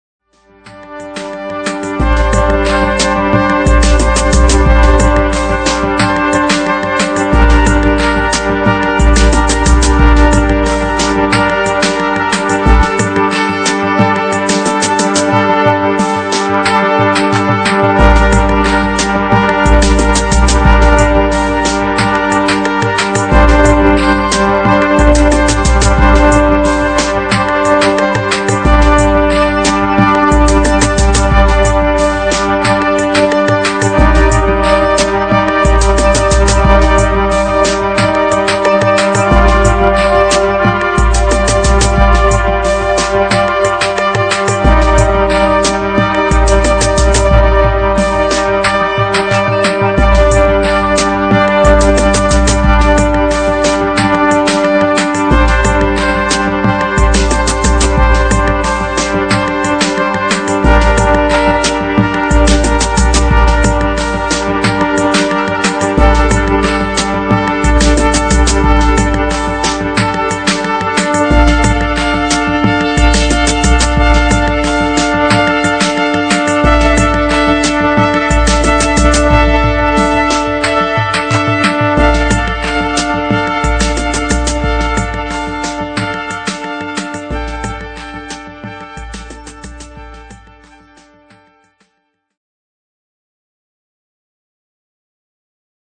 Maraton soudobé hudby 2004